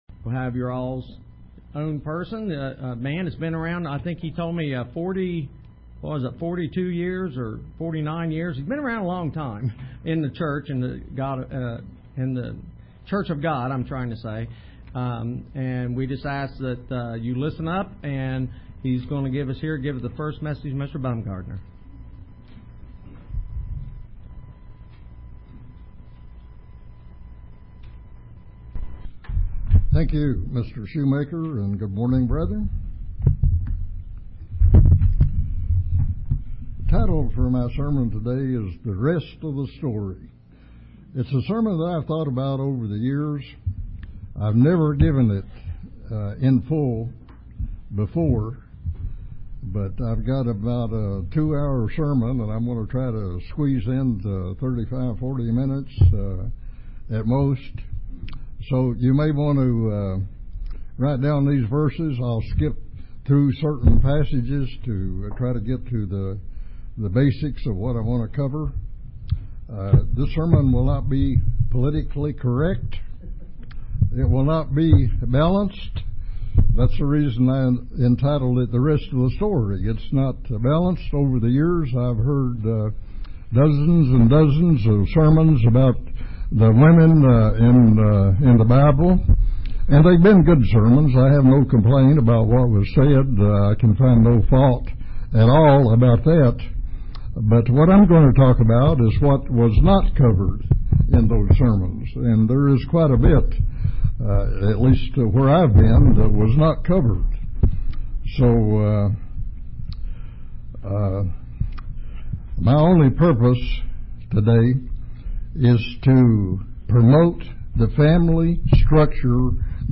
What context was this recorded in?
Given in Paintsville, KY